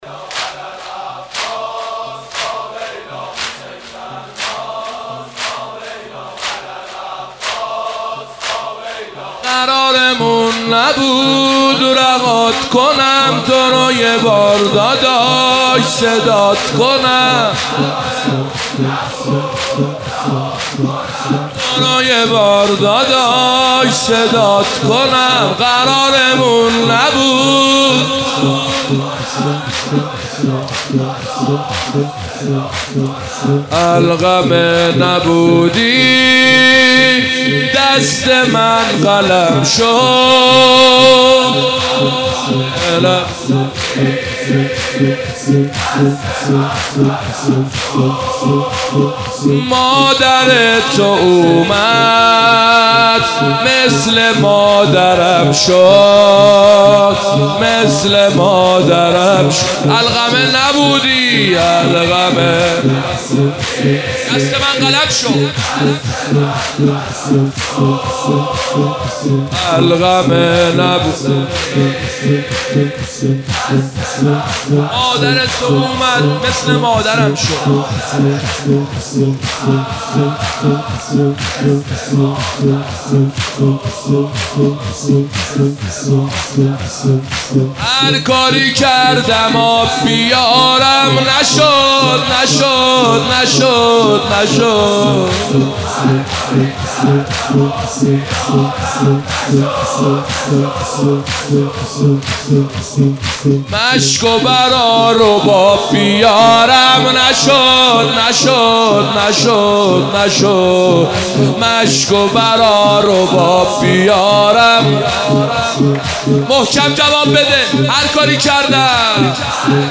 قالب : زمینه